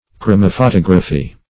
Meaning of chromophotography. chromophotography synonyms, pronunciation, spelling and more from Free Dictionary.
Search Result for " chromophotography" : The Collaborative International Dictionary of English v.0.48: Chromophotography \Chro`mo*pho*tog"ra*phy\, n. [Gr.